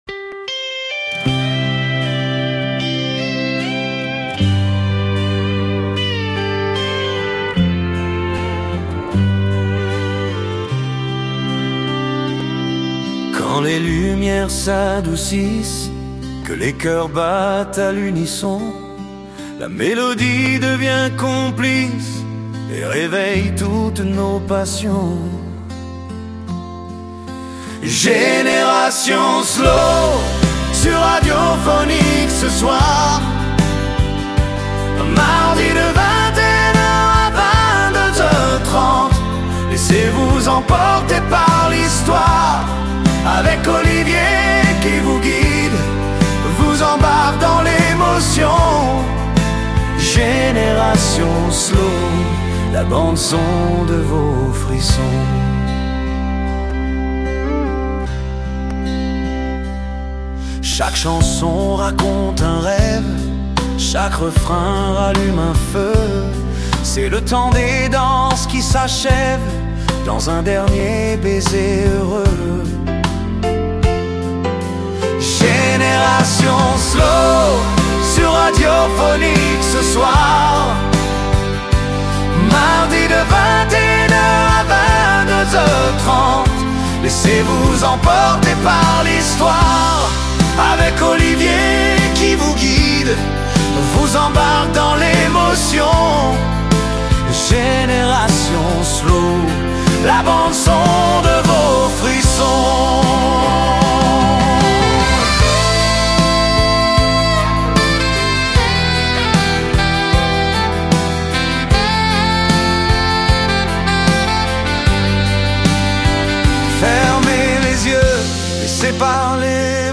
Au fil de cette émission du mardi 7 avril, laissez-vous porter par une sélection de chansons douces, intenses et intemporelles… Des voix qui racontent, des mélodies qui apaisent, et ces instants rares où la musique devient un refuge.
Une parenthèse musicale pleine de sensibilité, à savourer sans modération.